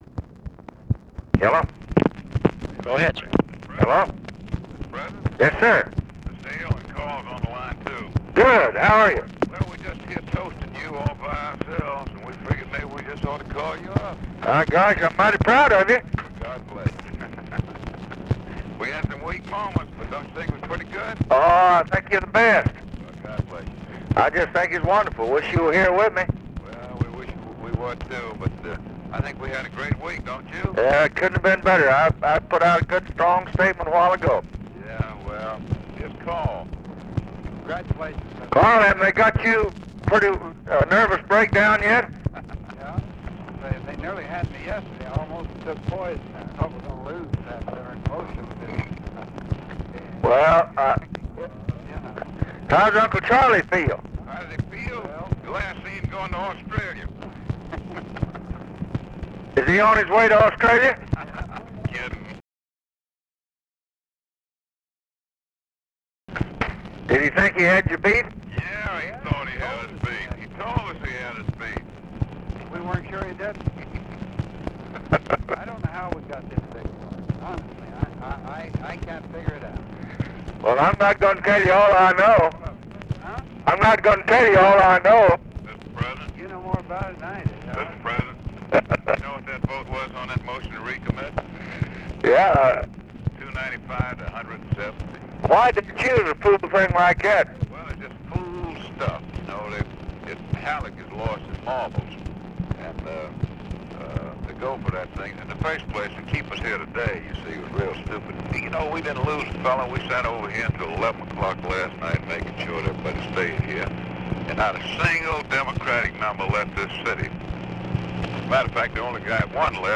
Conversation with HALE BOGGS and CARL ALBERT, August 8, 1964
Secret White House Tapes